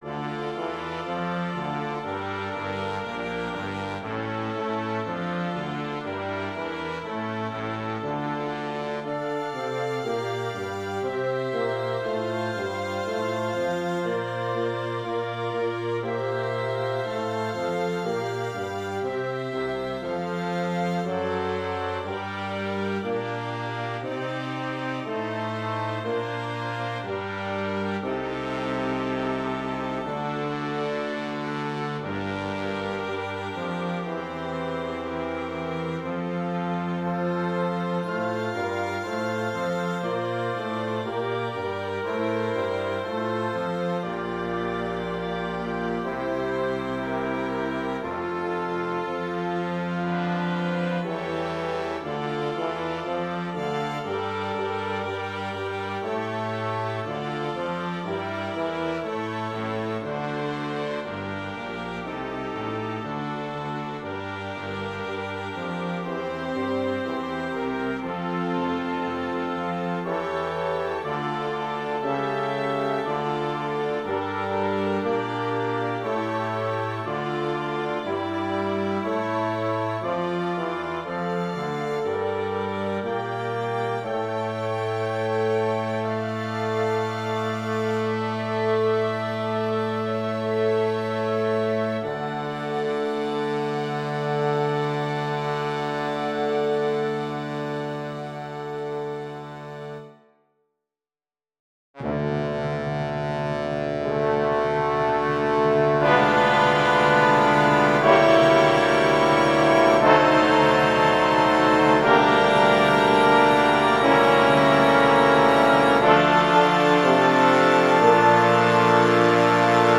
Prelude-Fugue-Orch.wav